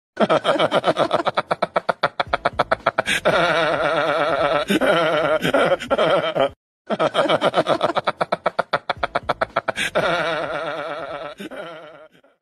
shah rukh khan Meme Sound Effect
This sound is perfect for adding humor, surprise, or dramatic timing to your content.